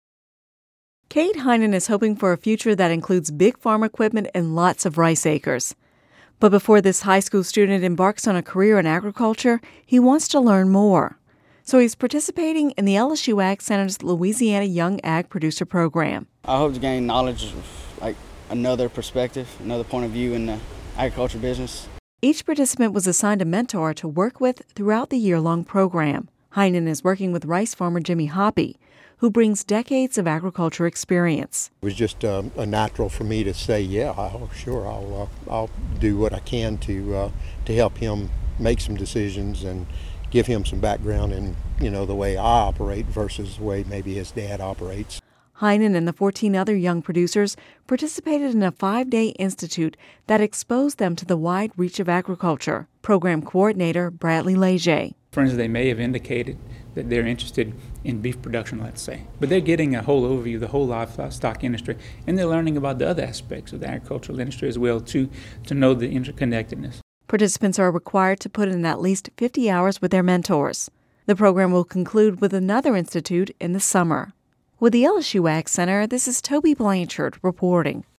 (Radio News 11/22/10) Fourteen high school juniors and seniors from across the state are participating in the LSU AgCenter’s Louisiana Young Ag Producer Program.